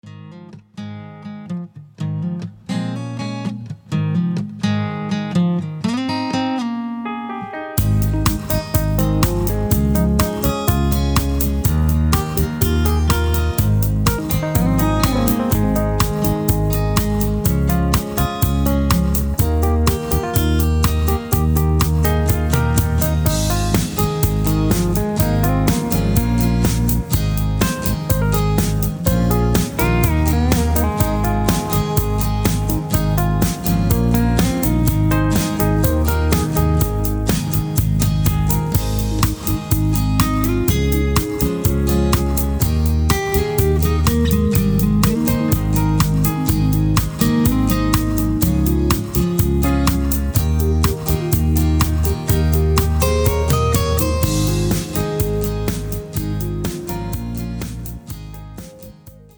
Singing Calls